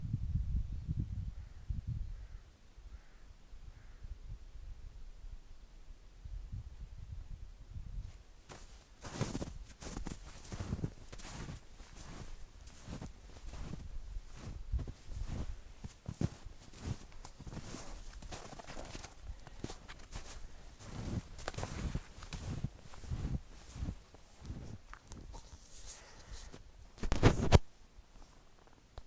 Walking in the snow